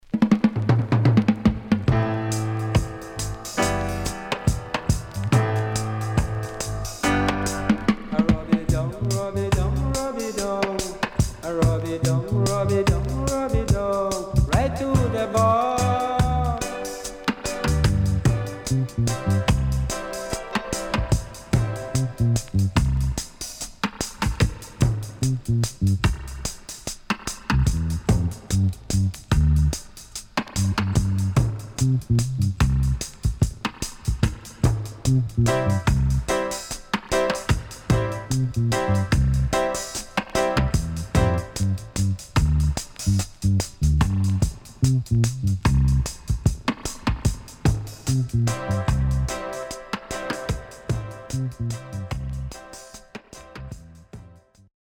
CONDITION SIDE A:VG(OK)〜VG+
Very Rare.Good Vocal & Dubwise.間奏いい感じです
SIDE A:少しチリノイズ入ります。